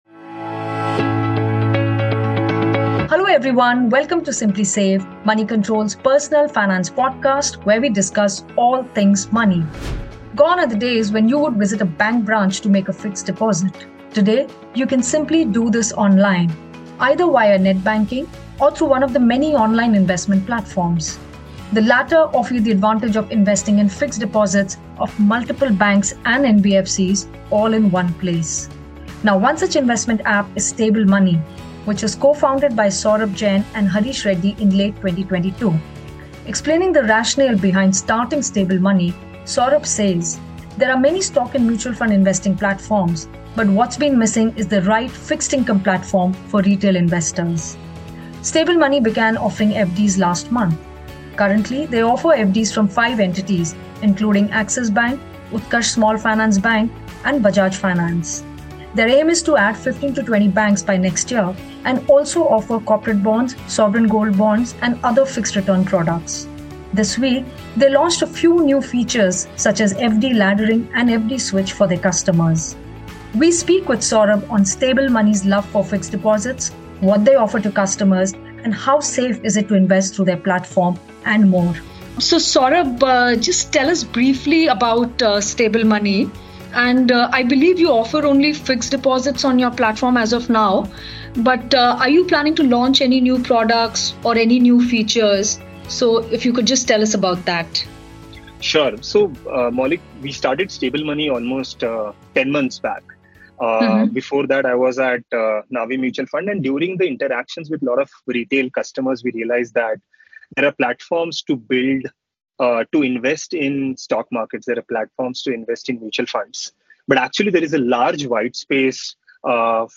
In a conversation with Moneycontrol